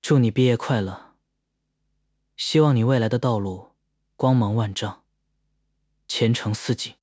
Text-to-Speech
Spark TTS finetuned in genshin charactors voices.